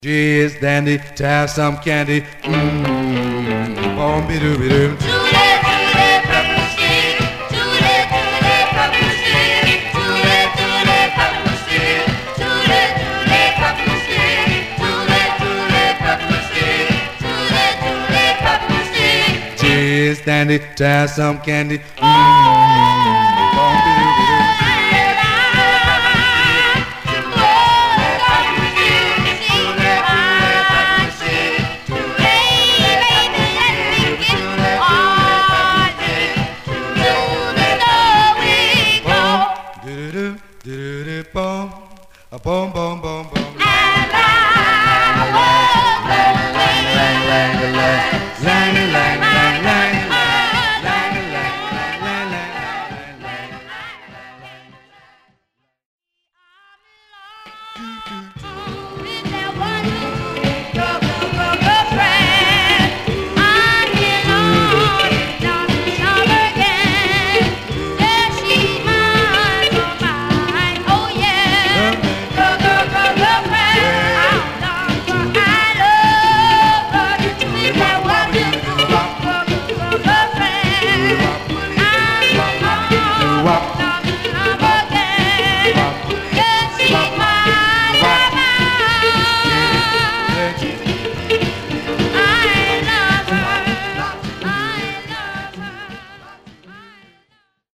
Some surface noise/wear
Mono
Male Black Group